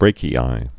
(brākē-ī, -kē-ē, brăkē-ī, -ē-ē)